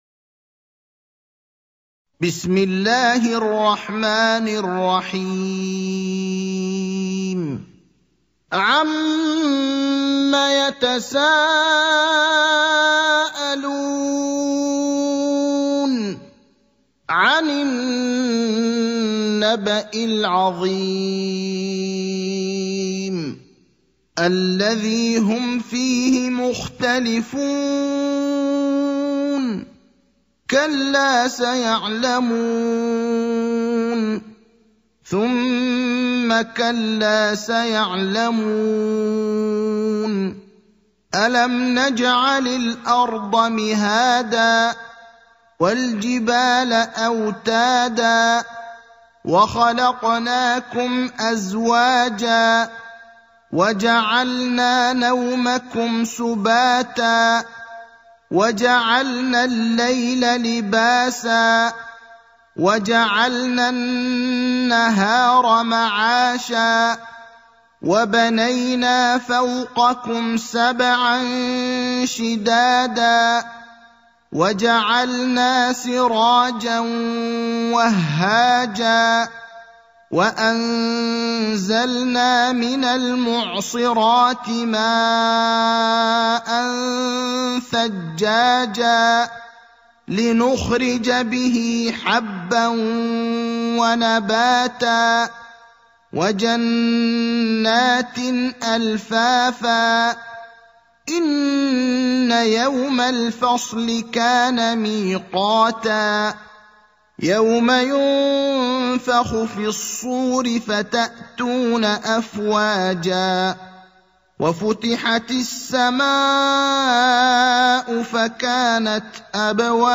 برواية حفص